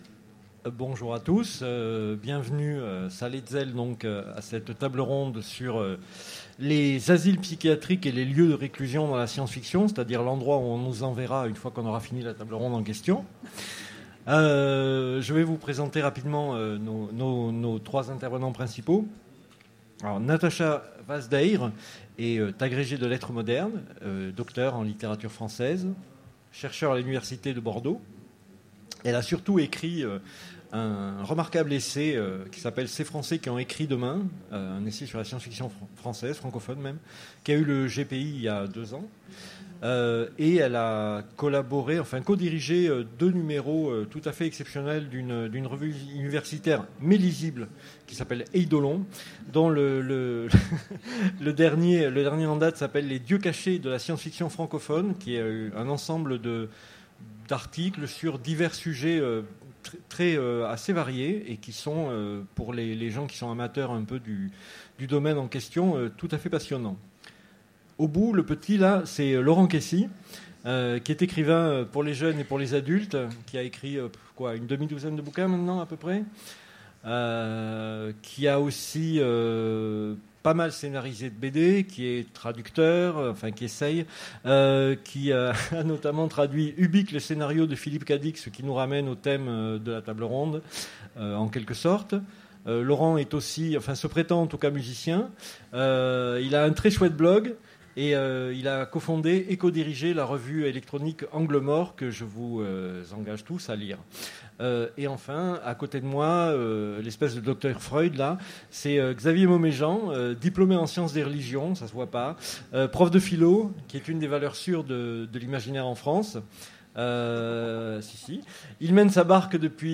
Utopiales 2015 : Conférence Asiles psychiatriques et lieux de réclusion dans la science-fiction